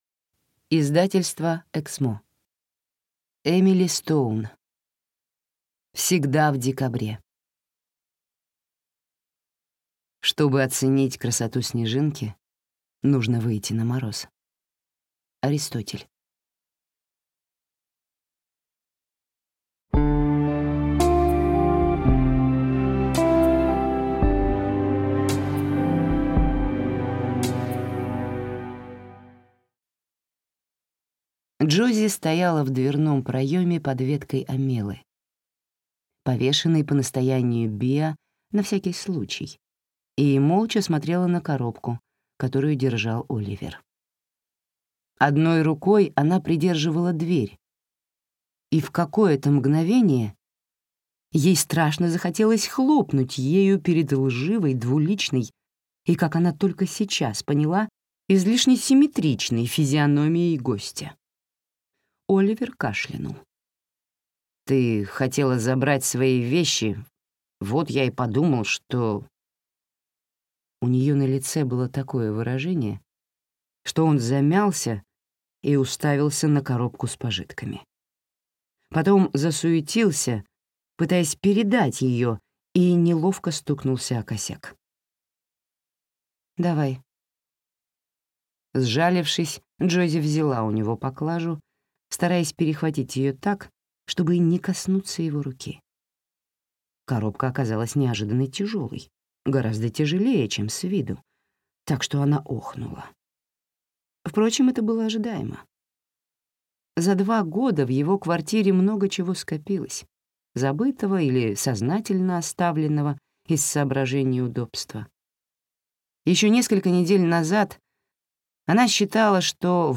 Аудиокнига Всегда в декабре | Библиотека аудиокниг
Прослушать и бесплатно скачать фрагмент аудиокниги